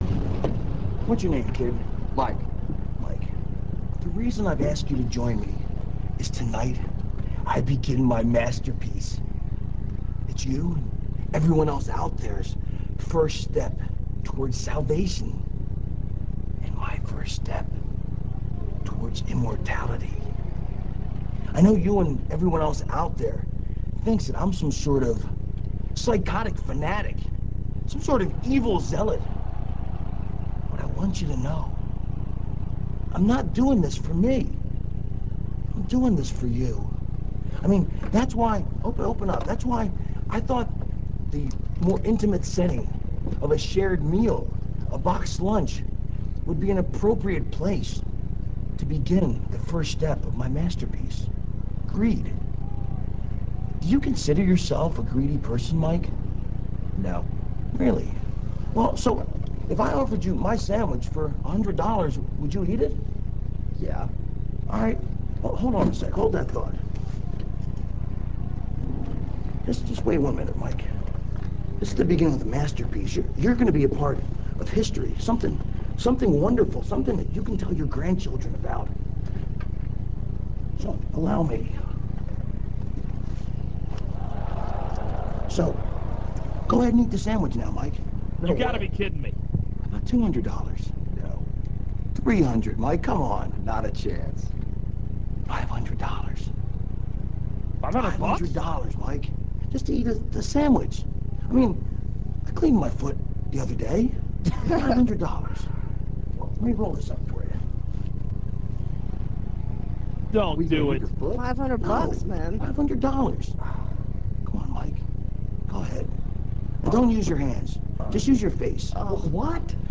raven84.rm - This clip comes from WWE HEAT - [12.08.02]. Raven unveils the first step of his 'masterpiece' - Greed.